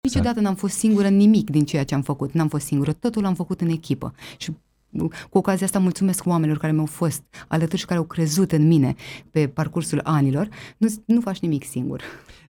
Invitată în emisiunea „Față în față”, la Viva FM, aceasta a vorbit despre planurile pe care le are pentru perioada următoare și despre legătura specială pe care o are cu acest loc.